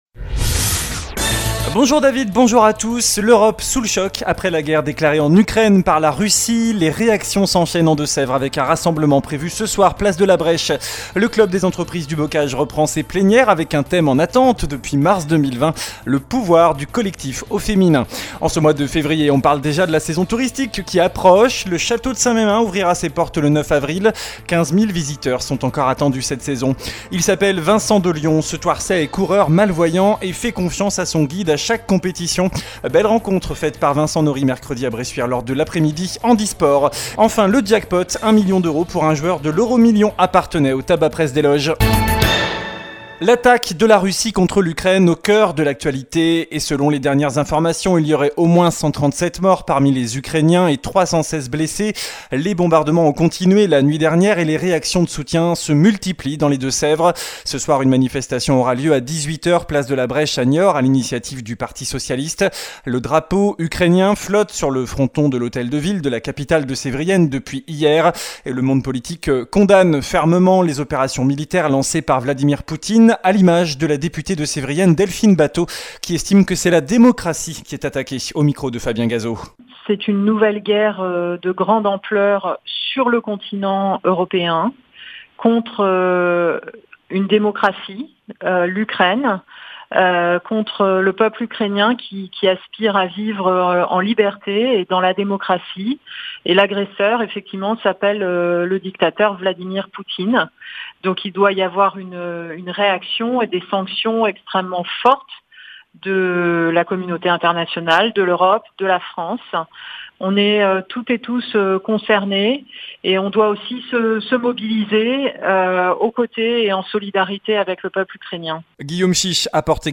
JOURNAL DU VENDREDI 25 FEVRIER ( MIDI )